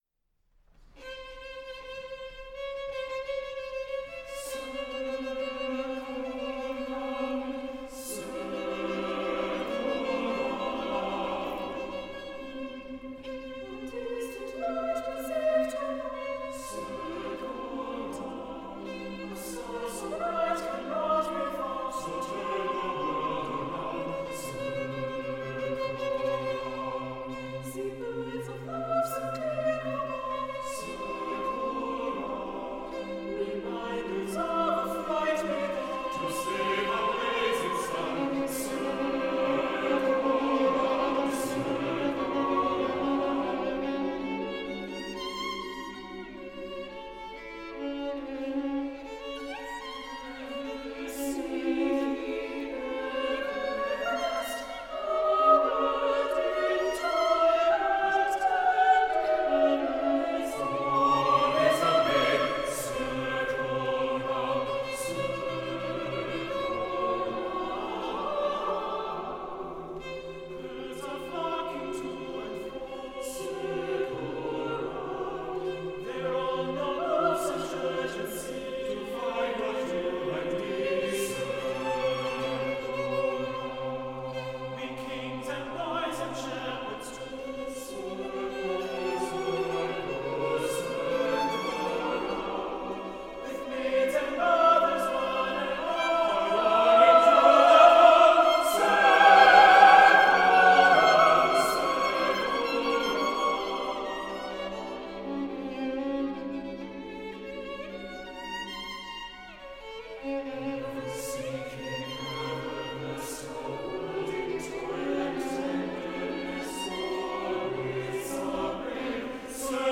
SATB Choir and Violin